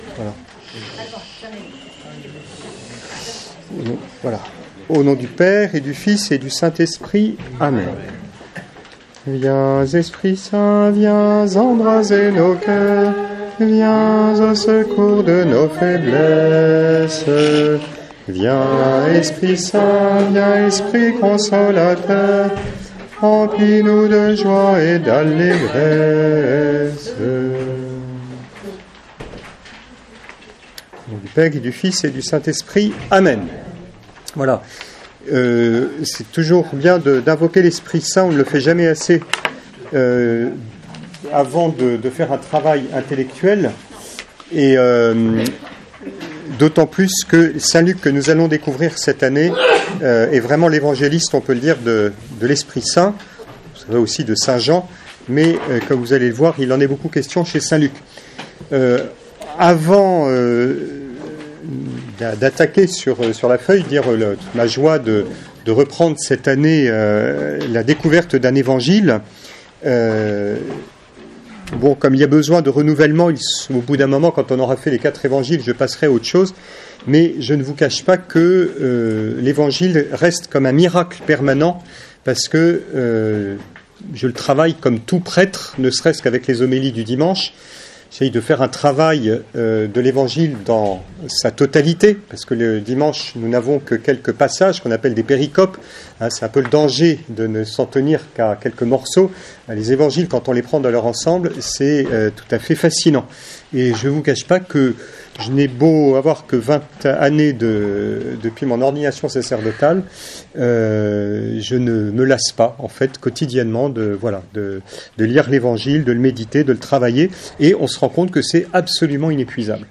1er cours : Introduction et présentation de l’Évangile selon Saint Luc